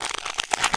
ALIEN_Insect_13_mono.wav